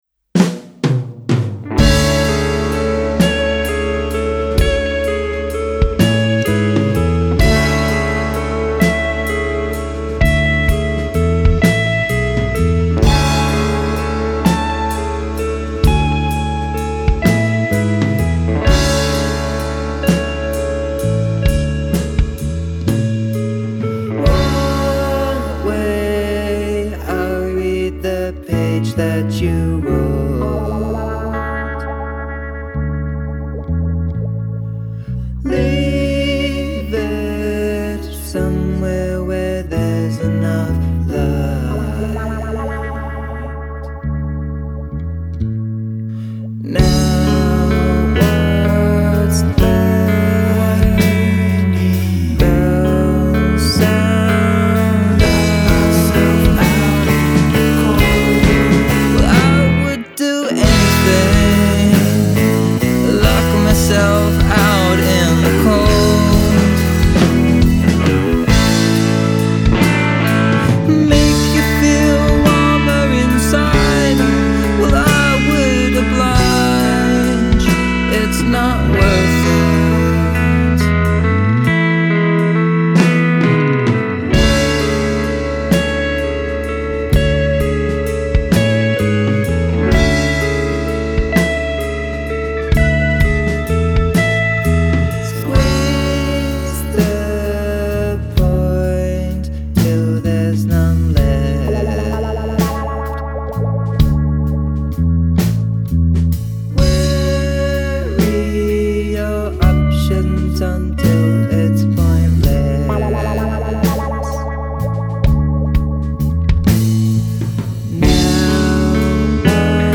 Genre: Indie Rock